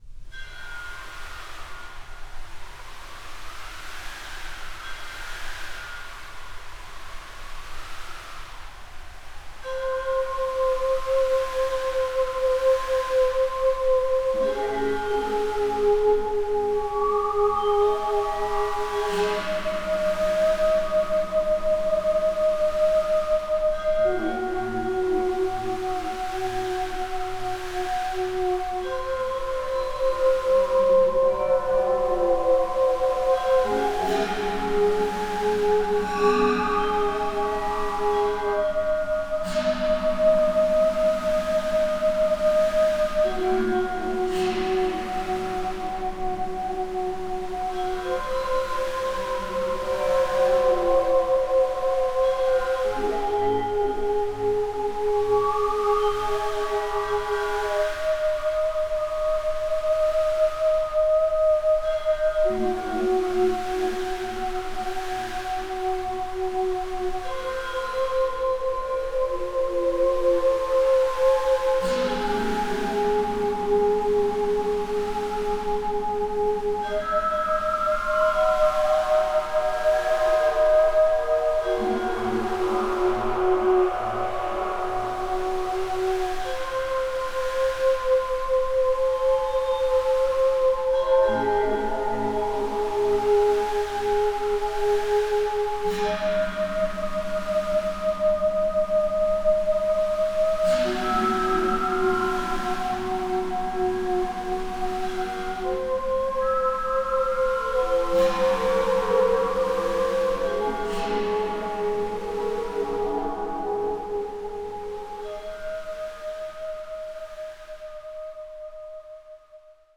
アンビエント
打楽器
怪しい
不安